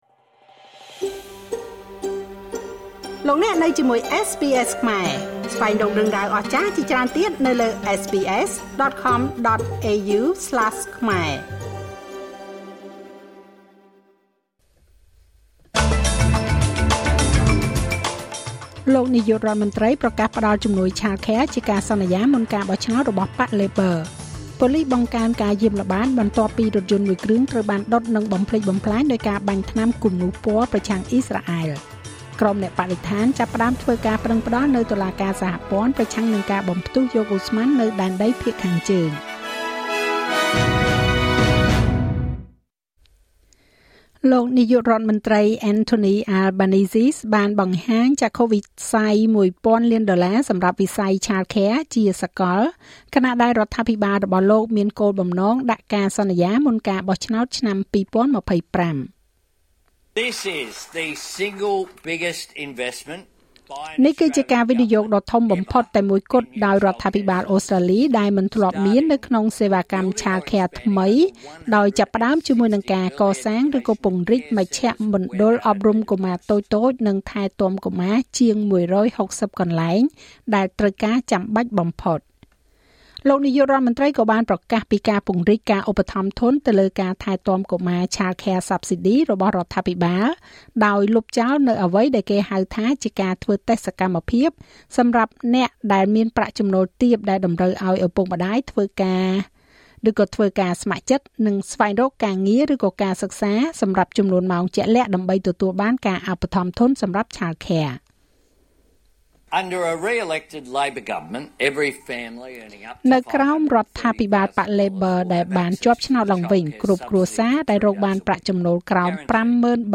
នាទីព័ត៌មានរបស់ SBSខ្មែរ សម្រាប់ ថ្ងៃពុធ ទី១១ ខែធ្នូ ឆ្នាំ២០២៤